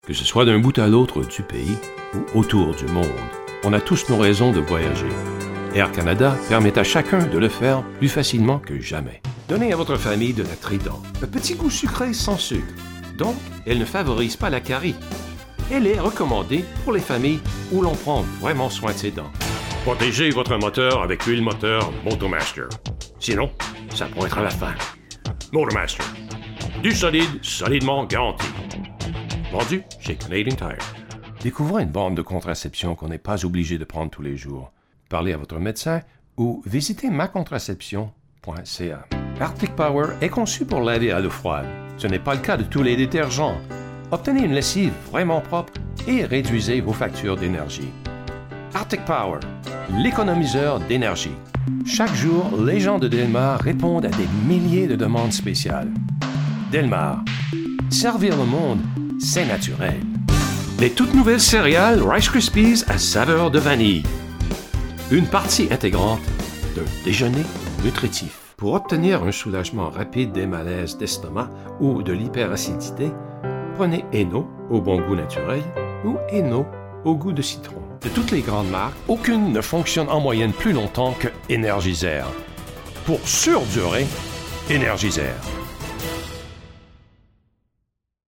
I\'ve done bilingual voice over for the Canadian market for 15 yrs.
Sprechprobe: Werbung (Muttersprache):